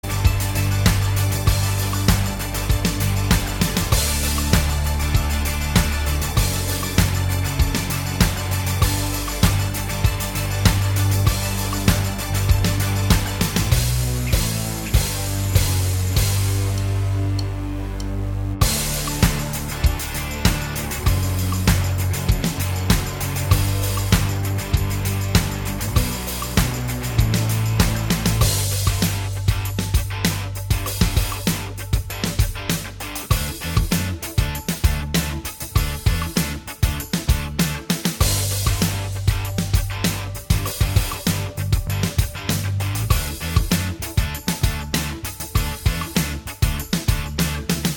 Minus All Guitars Pop (2010s) 3:32 Buy £1.50